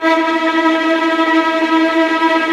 VIOLINT F#-R.wav